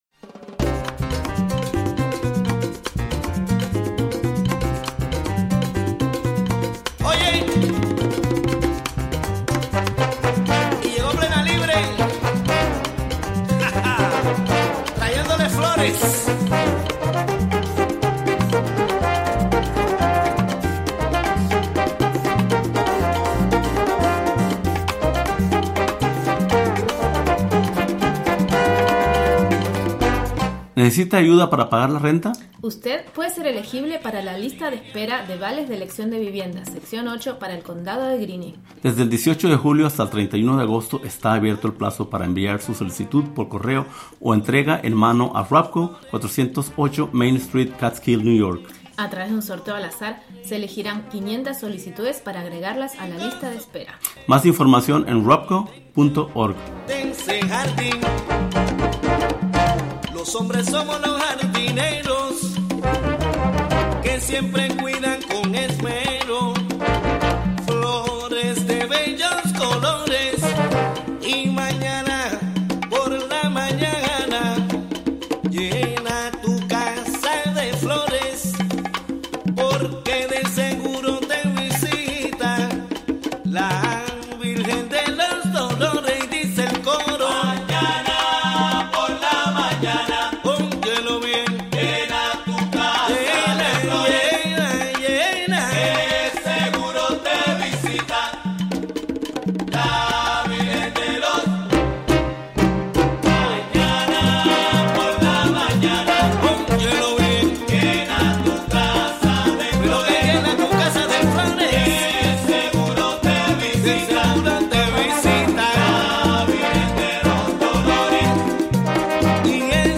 11am Un programa imperdible con noticias, entrevistas,...